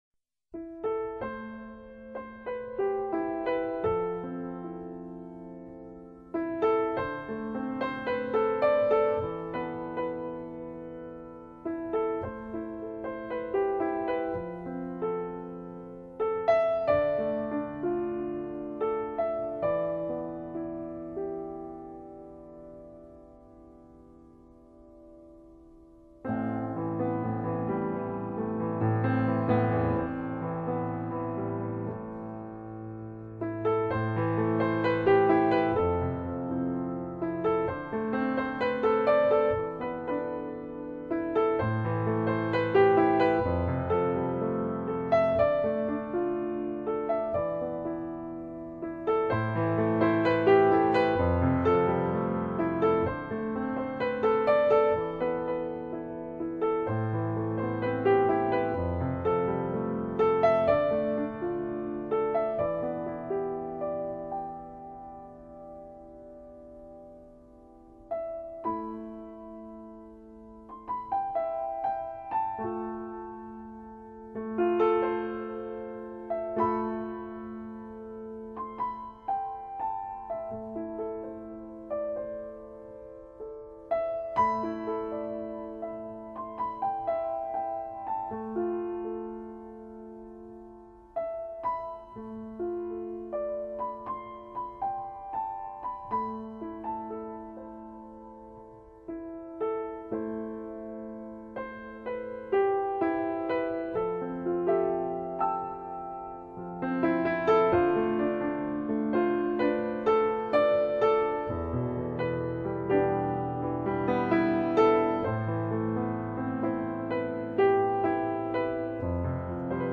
接下来将以四首纯钢琴作品，回归到New Age曲风上。